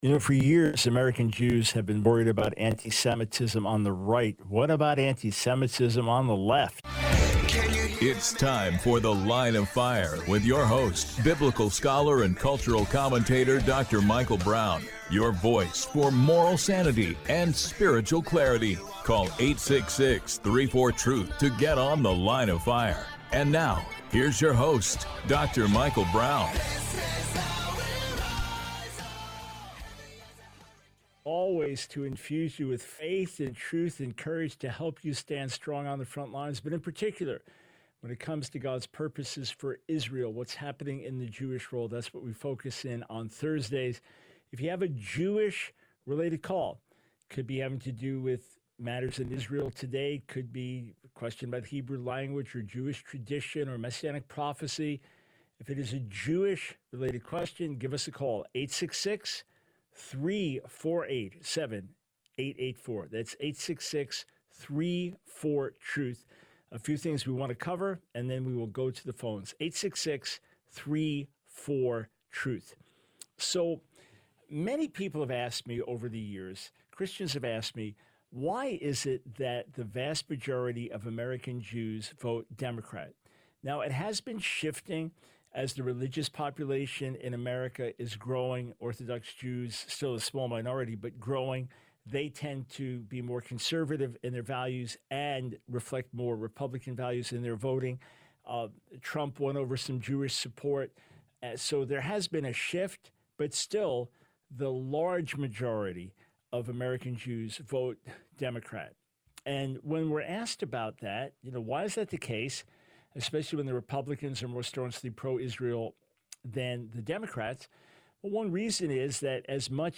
The Line of Fire Radio Broadcast for 09/18/24.